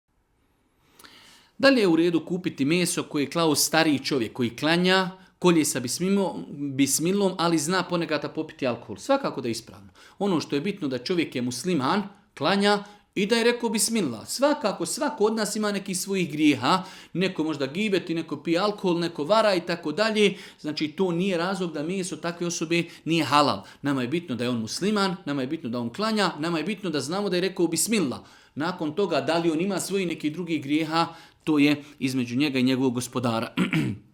u video predavanju ispod.